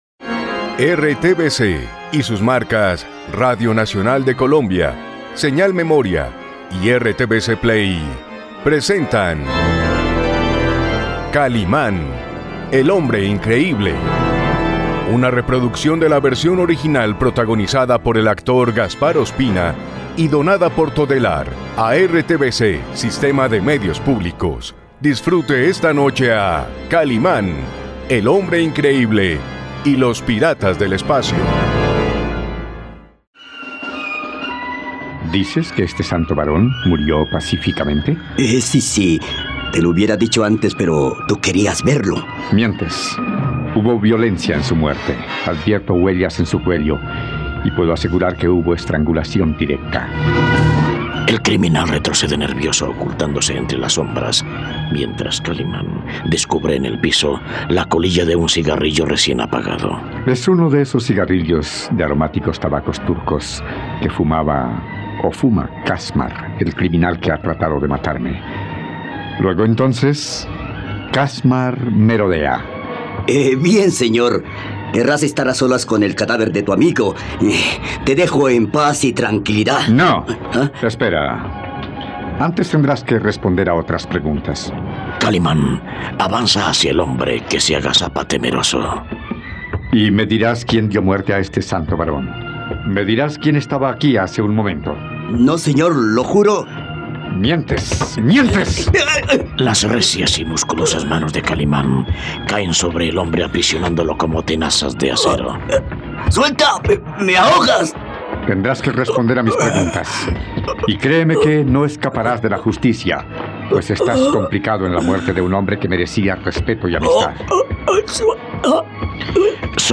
Ayúdalo a descubrir el misterio de este crimen en la radionovela de 'Kalimán y los piratas del espacio', aquí por RTVCPlay.